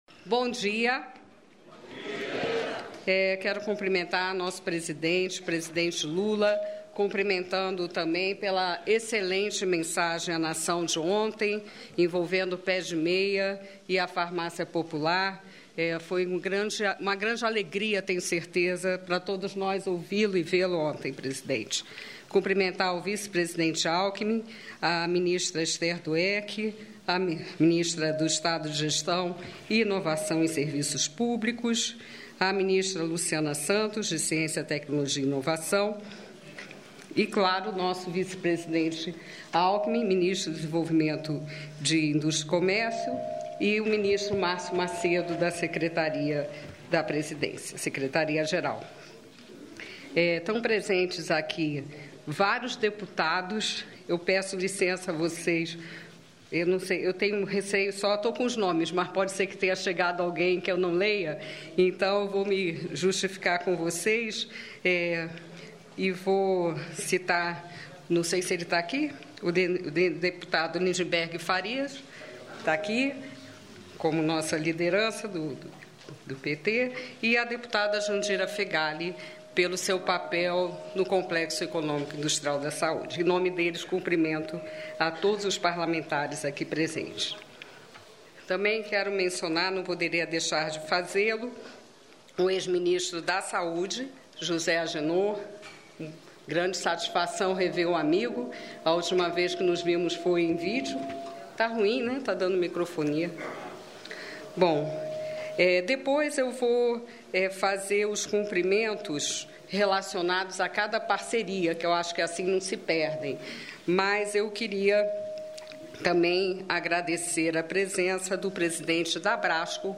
Íntegra da entrevista coletiva concedida pelo vice-presidente e ministro do Desenvolvimento, Indústria, Comércio e Serviços, Geraldo Alckmin, nesta sexta-feira (28), em Brasília.